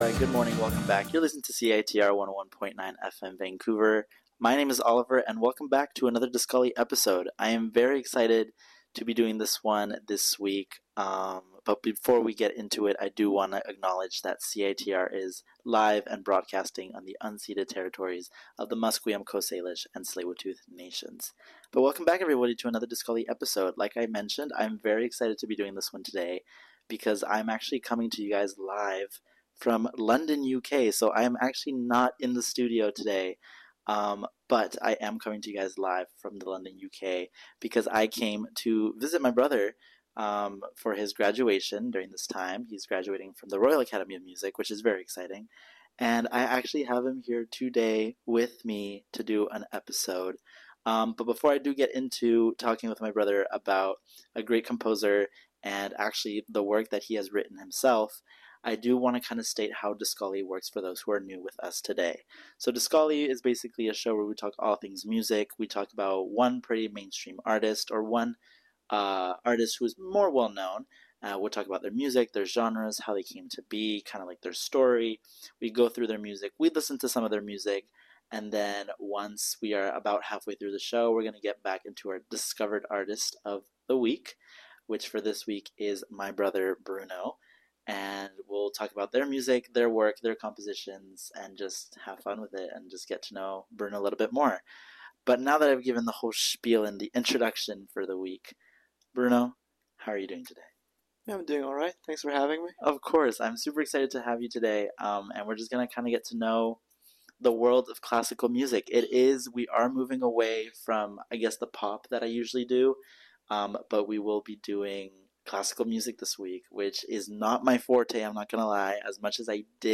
This episode takes place over international waters, coming to you from London, UK.